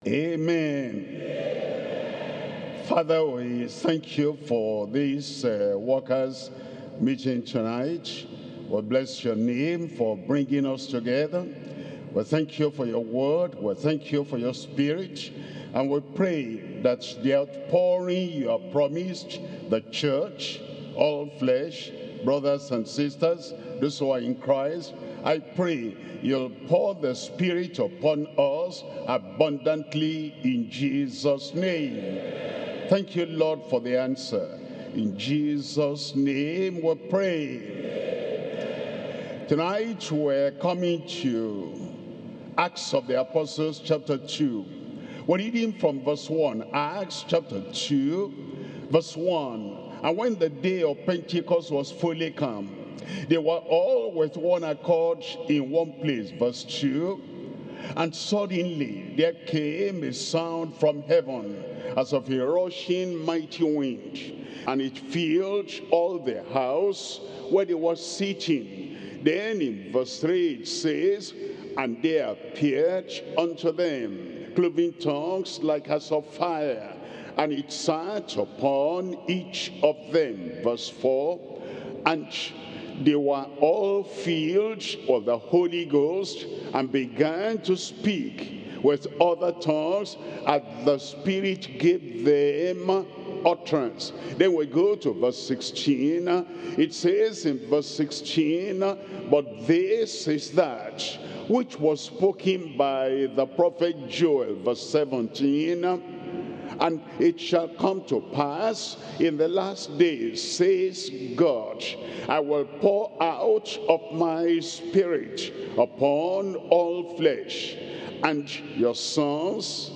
SERMONS – Deeper Christian Life Ministry Australia